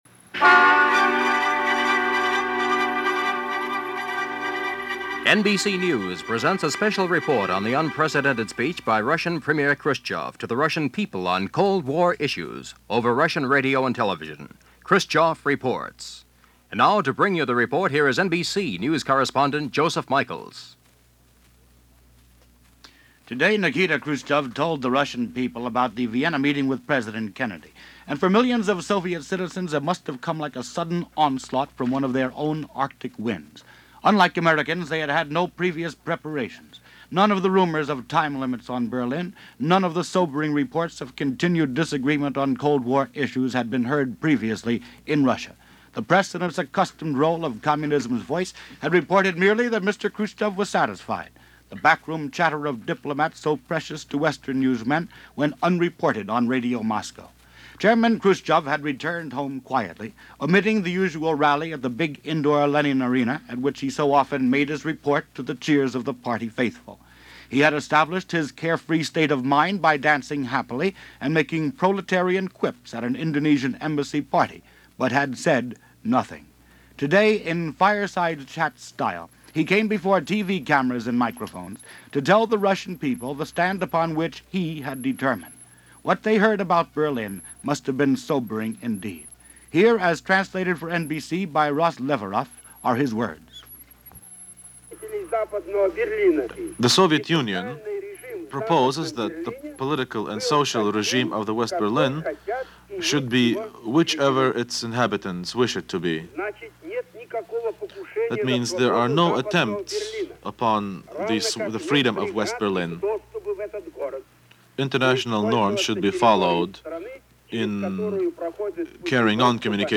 Click on the link here for Audio Player – NBC Radio Special Report – Nikita Khruschev Reports – Jan. 16, 1961